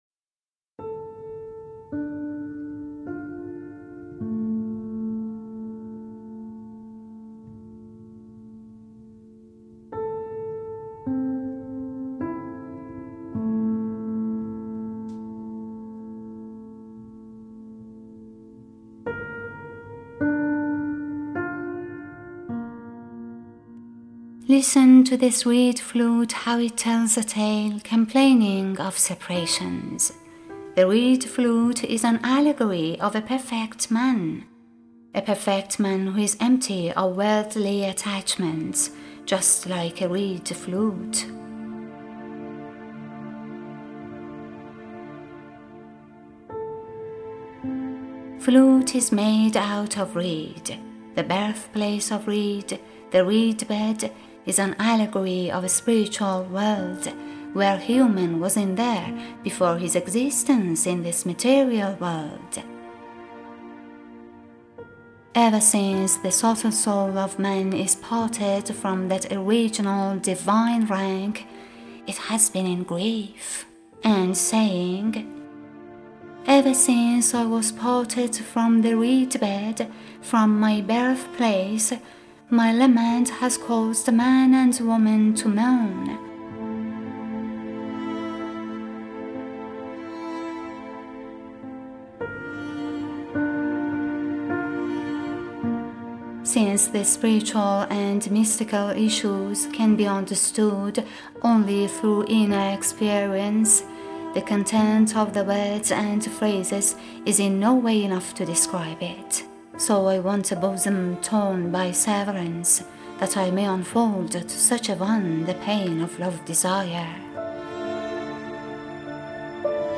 Narrator and Producer: